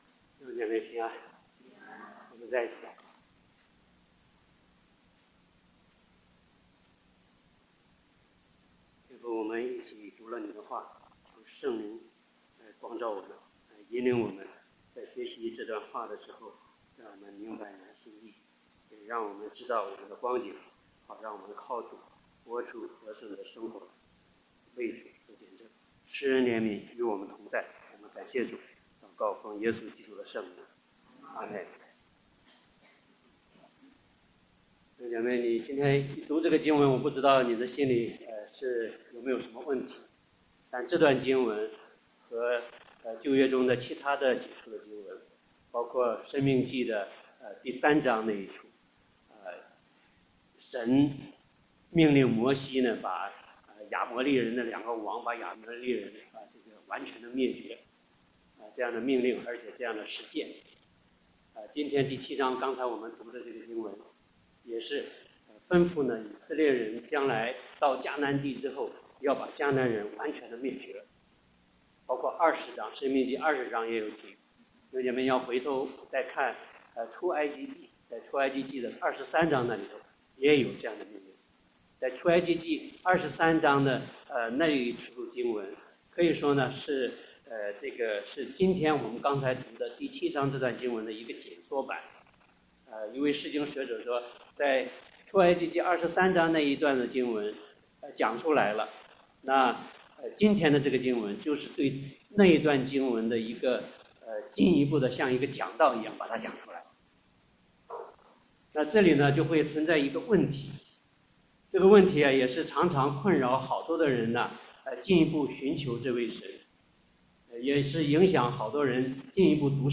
崇拜講道錄音